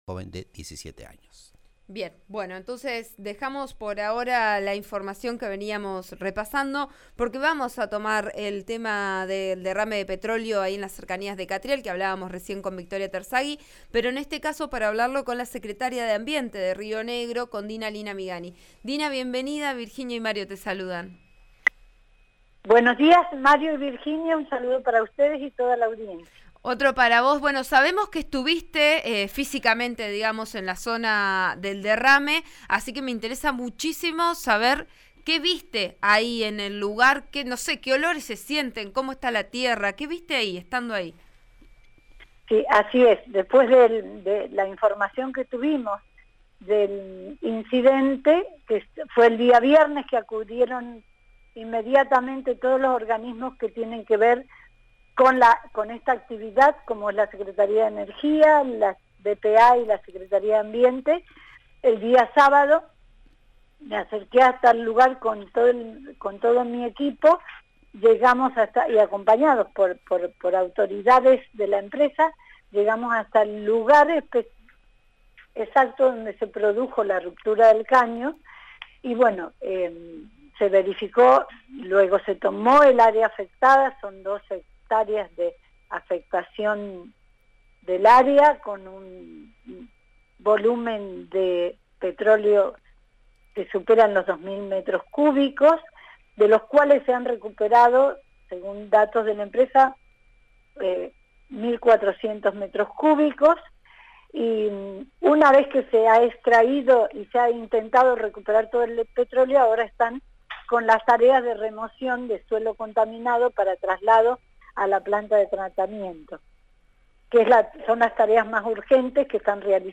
Dina Lina Migani, secretaria de Ambiente de Río Negro, explicó al programa Vos A Diario de RN RADIO los trabajos que se llevan adelante y contó que hasta ayer se había retirado más de 30 camiones con áridos para su tratamiento en una planta ambiental. Es parte de las tareas que se están realizando: se remueve el suelo hasta llegar a la superficie no contaminada.